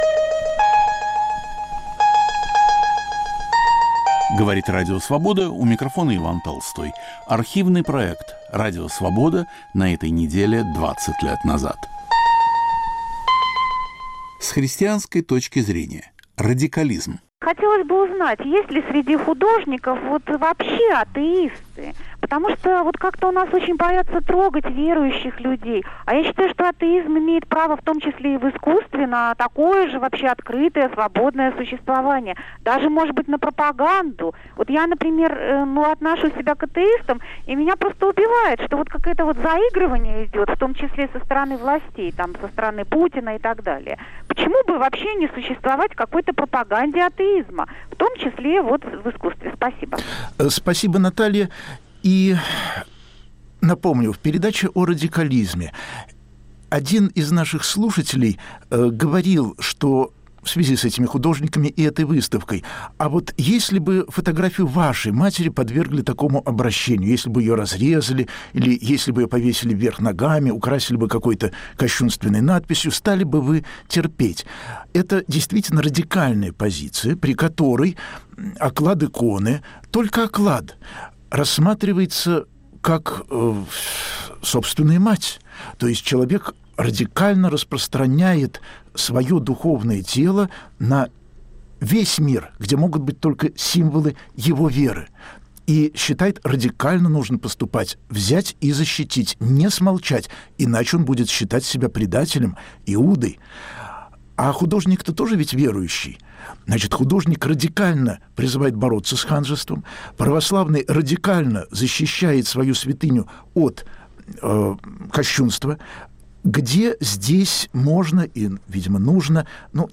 Гости в студии - художники и религиовед, и позвонившие слушатели обсуждают в прямом эфире радикализм в искусстве и выставку "Осторожно, религия!" в Сахаровском центре.